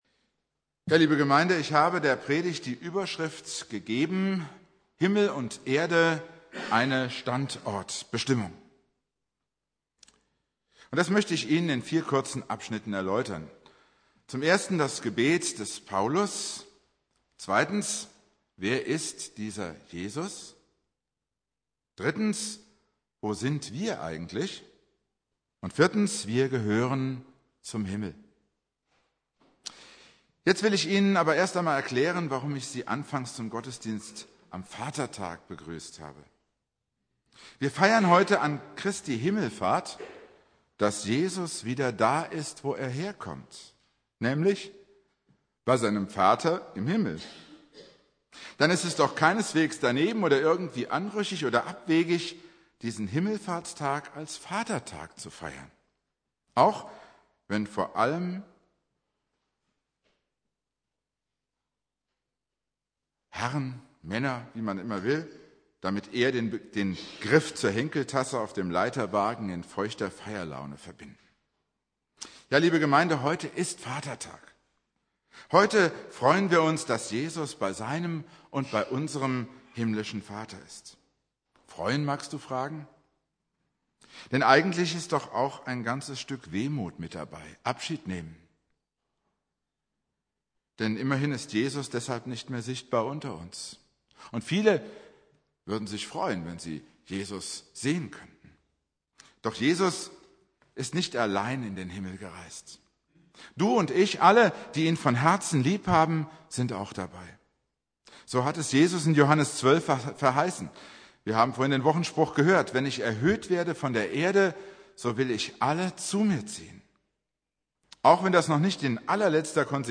Predigt
Christi Himmelfahrt